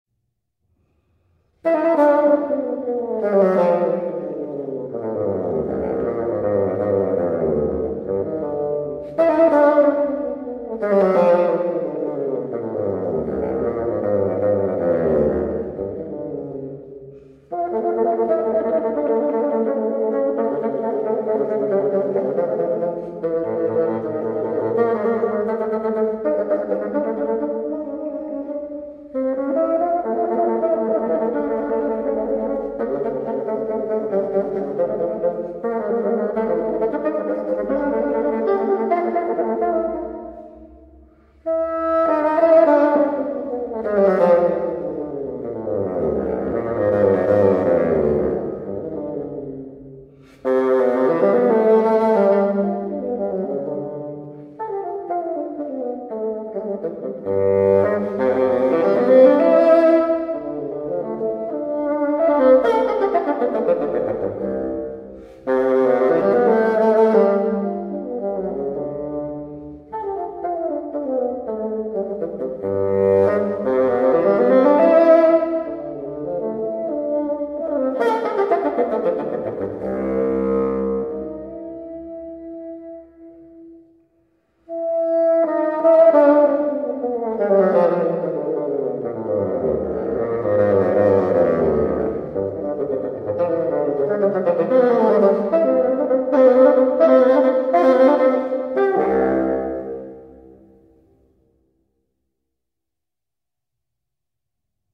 fagote